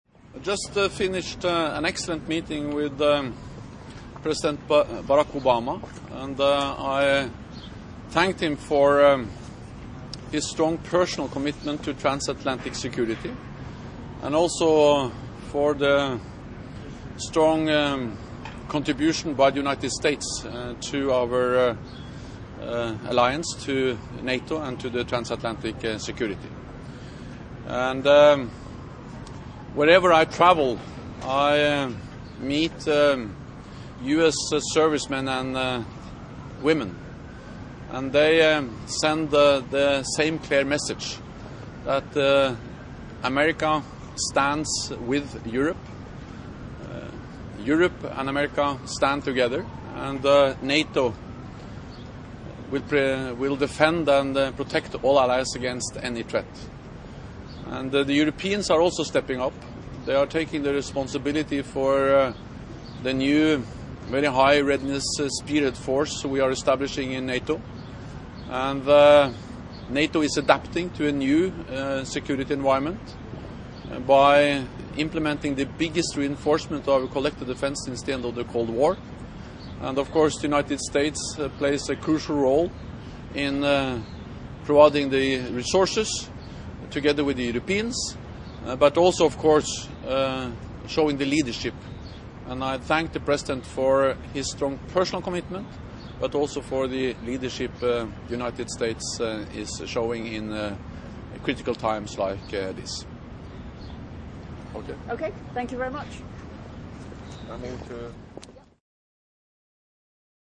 Statement by NATO Secretary General Jens Stoltenberg following his meeting with US President Barack Obama at the White House 26 May. 2015 | download mp3 Joint press statements by US President Barack Obama and NATO Secretary General Jens Stoltenberg at the White House 26 May. 2015 | download mp3